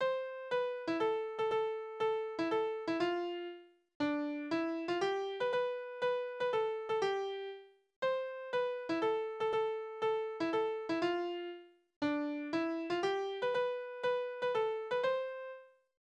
Spottverse auf Namen: Laura
Tonart: C-Dur
Taktart: 4/8
Tonumfang: große Sexte
Besetzung: vokal
Anmerkung: Polka